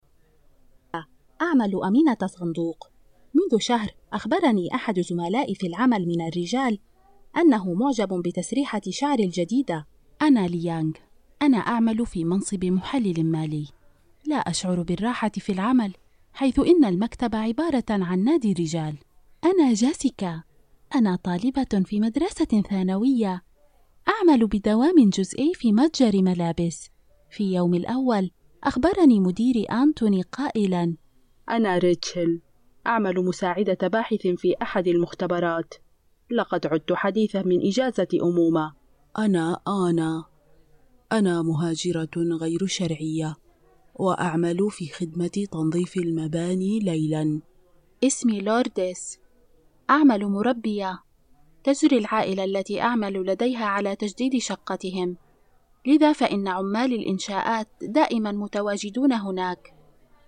阿拉伯语女声 声音多变 低沉|激情激昂|大气浑厚磁性|沉稳|娓娓道来|科技感|积极向上|时尚活力|神秘性感|调性走心|亲切甜美|感人煽情|素人|脱口秀